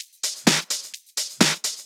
Index of /VEE/VEE Electro Loops 128 BPM
VEE Electro Loop 344.wav